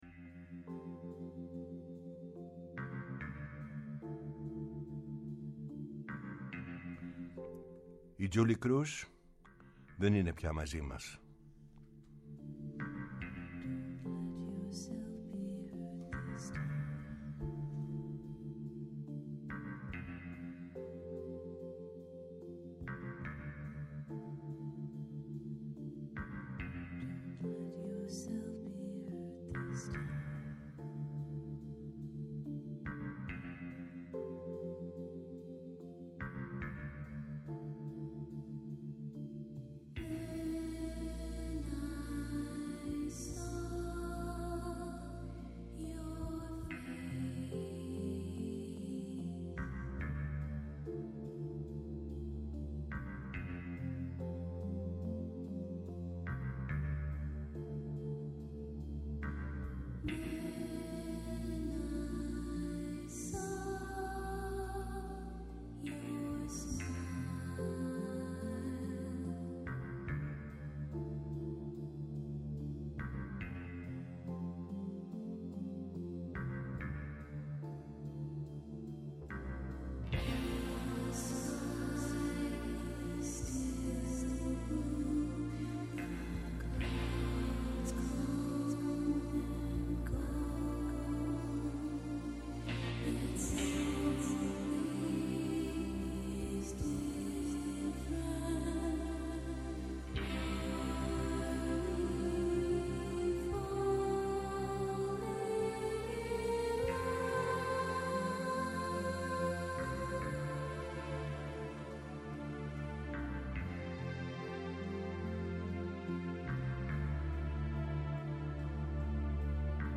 Η μακροβιότερη εκπομπή στο Ελληνικό Ραδιόφωνο!
ΜΟΥΣΙΚΗ